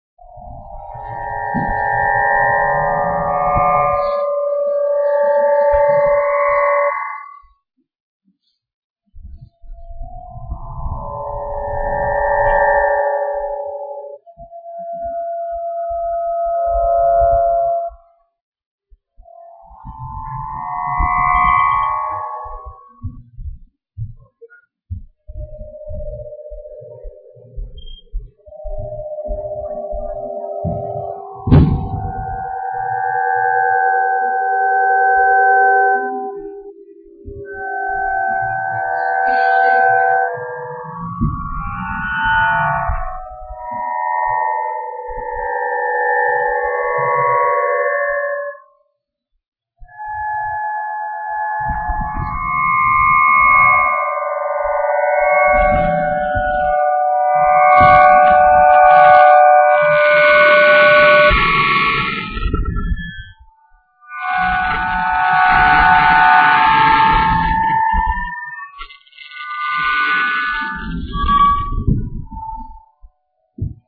Aeolian Harp Recordings
An aeolian harp (�olian harp or wind harp) is a musical instrument that is "played" by the wind.
The traditional aeolian harp is essentially a wooden box including a sounding board, with strings stretched lengthwise across two bridges. It is placed in a slightly opened window where the wind can blow across the strings to produce sounds.